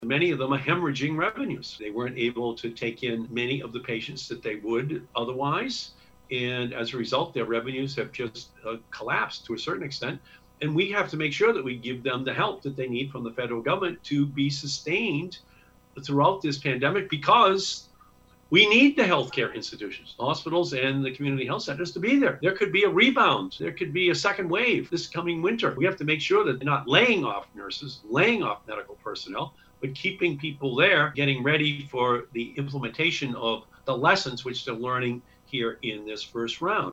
U.S. Senator Ed Markey Spoke shortly after and discussed the need for continued federal funding for local hospitals and health care centers.